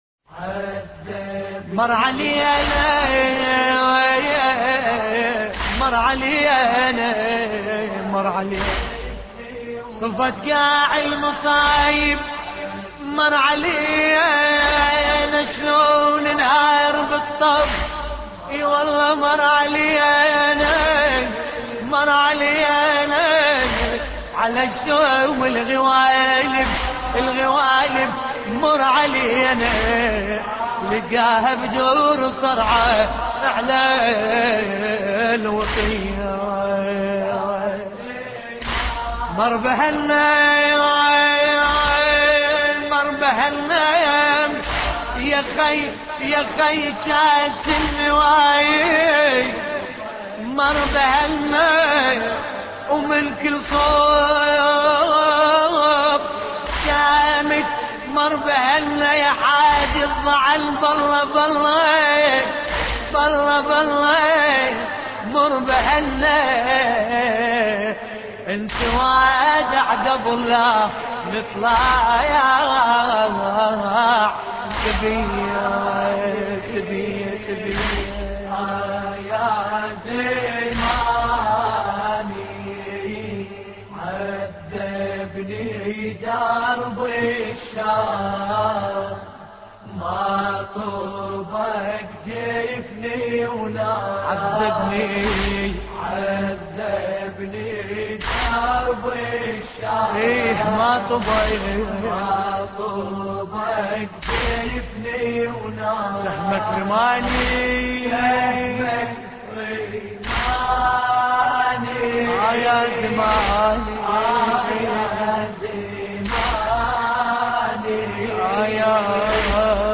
مراثي أهل البيت (ع)